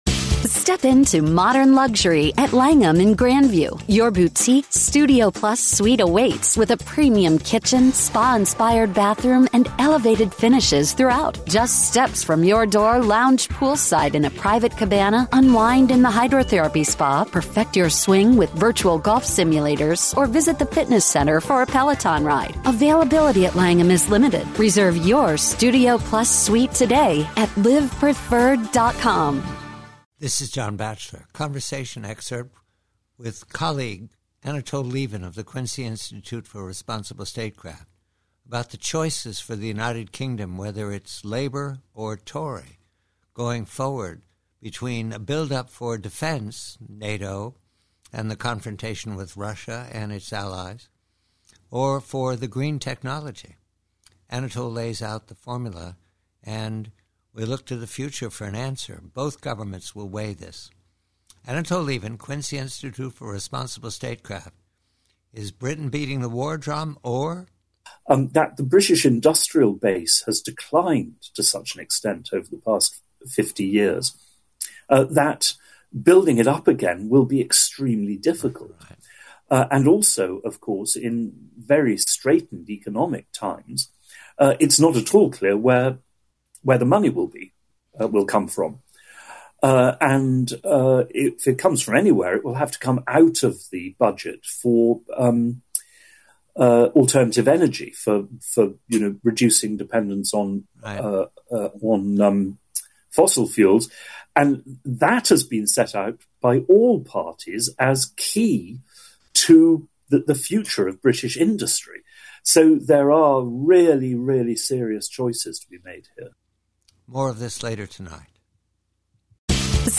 PREVIEW-UK: Conversation with colleague Anatol Lieven of Quincy Institute re the hard choice the next British government needs to make between arms and Green.